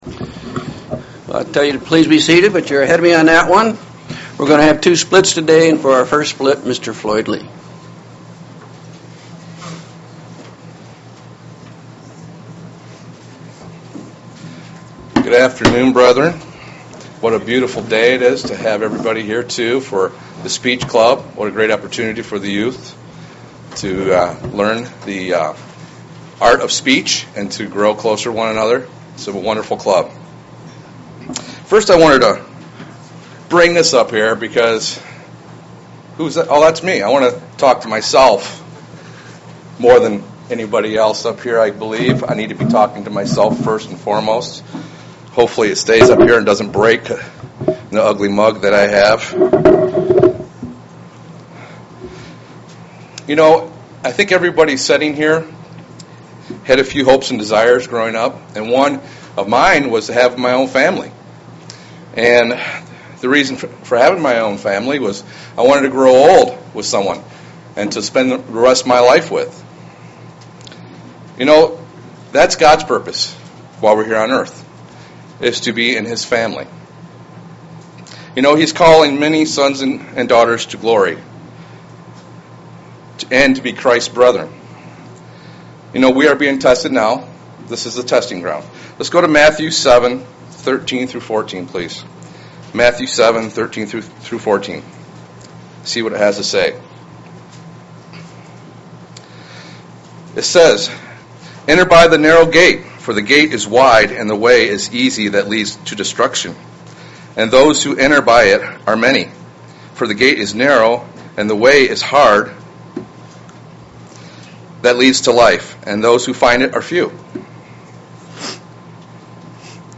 Print Six steps in becoming a child of God UCG Sermon Studying the bible?
Given in Lansing, MI